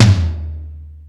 Metal Drums(25).wav